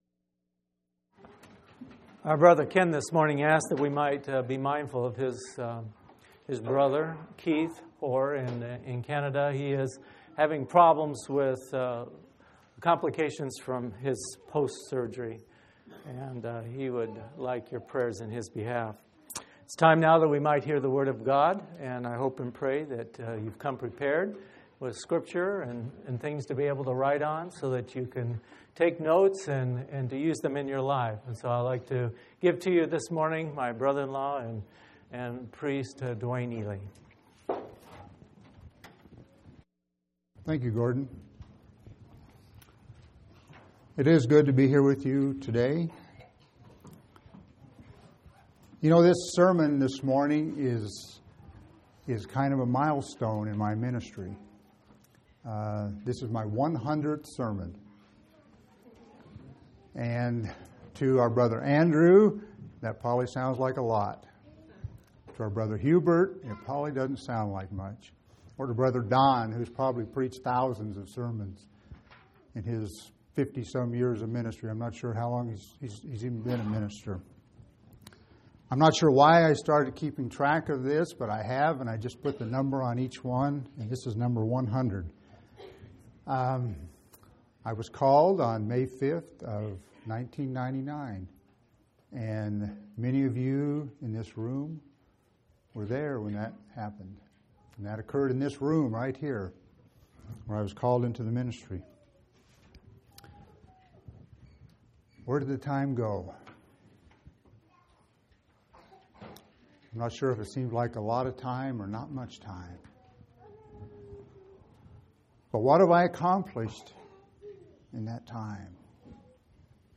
2/26/2006 Location: Phoenix Local Event